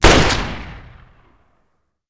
wpn_pistol32_fire.wav